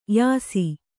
♪ yāsi